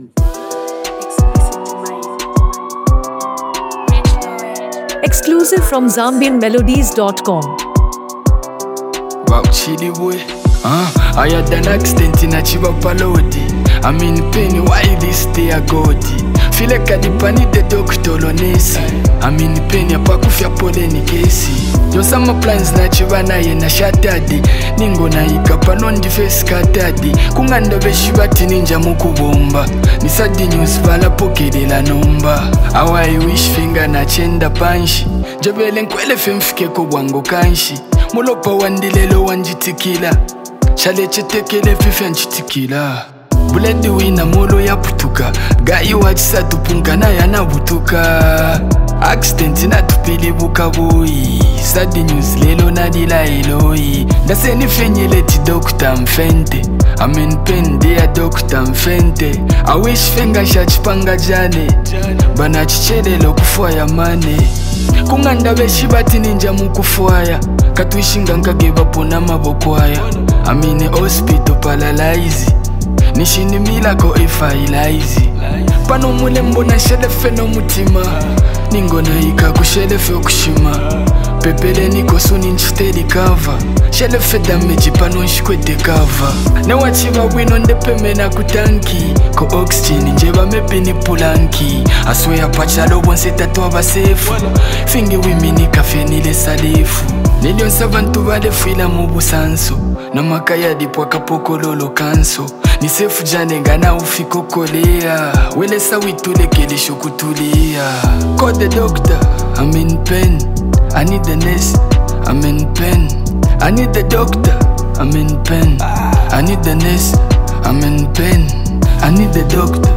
With heartfelt delivery